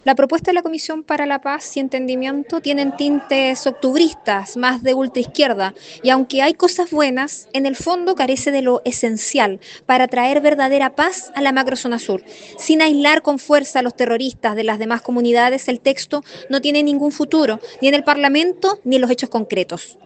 Desde la otra vereda política, la senadora de Renovación Nacional, María José Gatica, sostuvo que la propuesta posee tintes “octubristas”.